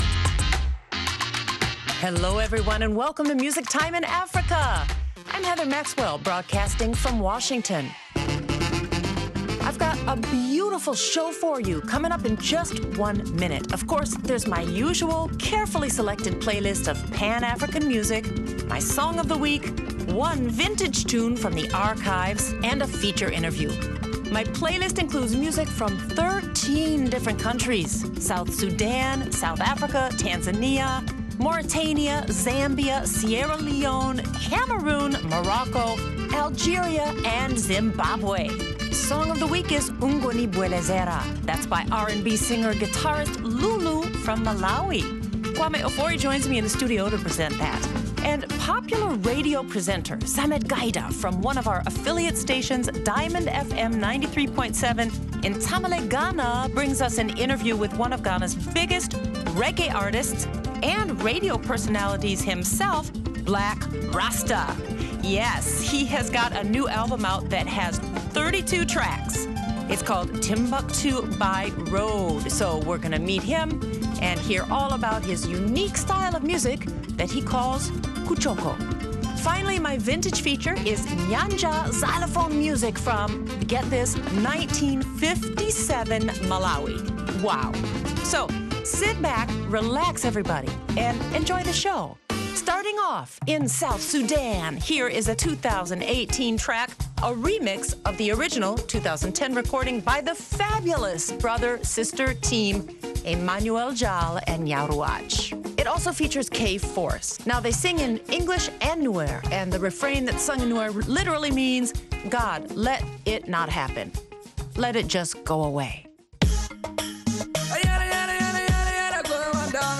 Blakk Rasta is my feature interview